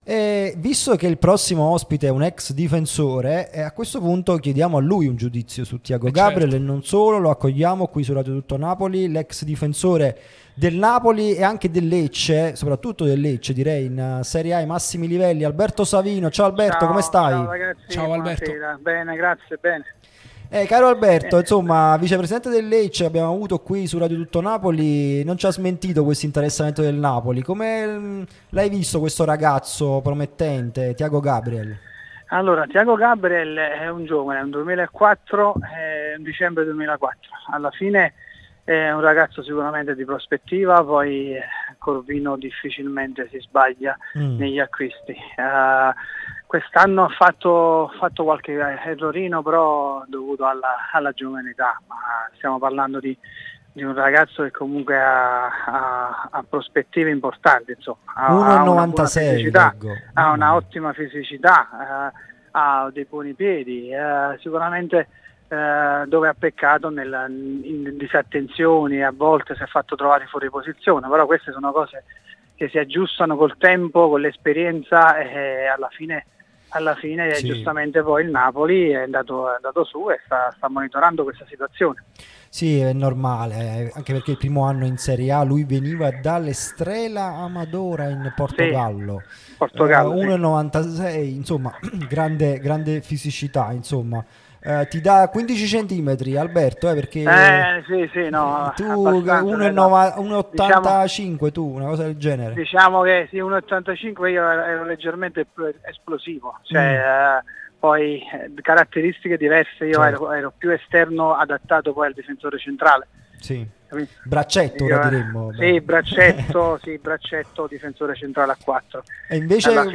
Radio Tutto Napoli
trasmissione sulla nostra Radio Tutto Napoli